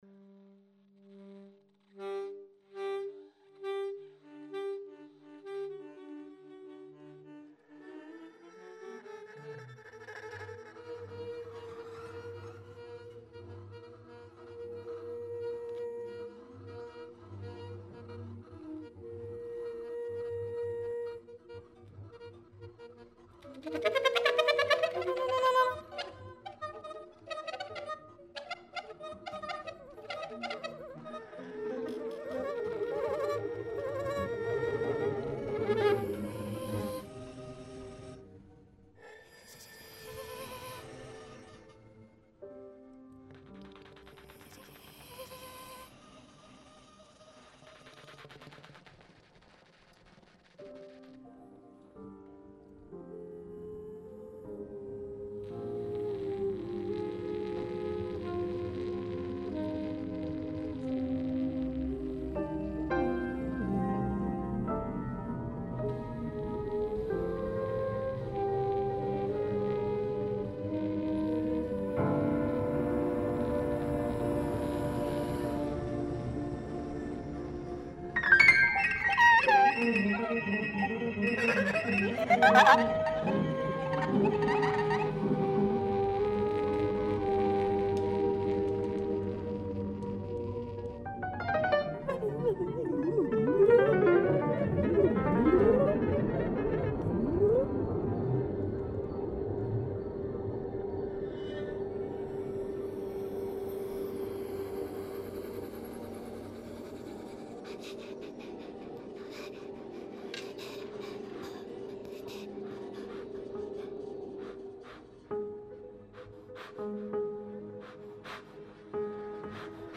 Ensemble Workshop
Schloß Weinberg OÖ
Improvisierte instrumentale Musik und Echtzeit-Computerprozesse interagieren und beeinflussen sich gegenseitig:
Workshop Session mp3
Ensemble